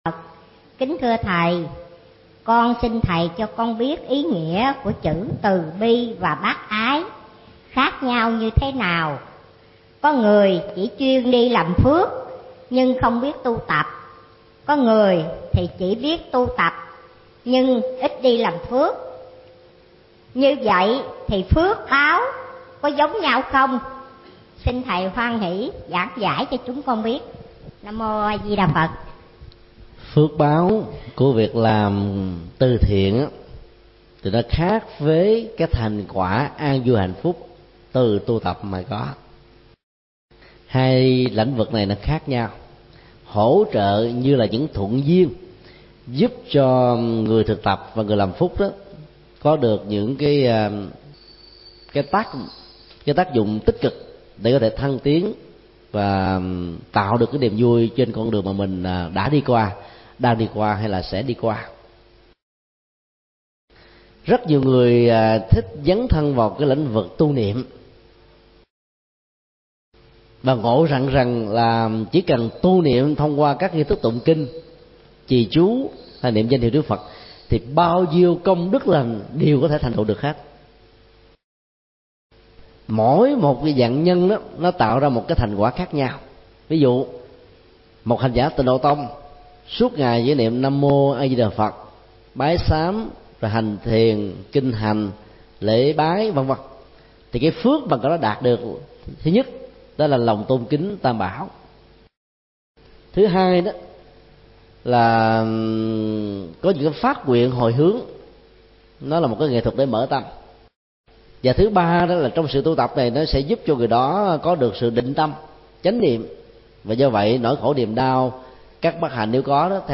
Vấn đáp: So sánh ý nghĩa “từ bi – bác ái”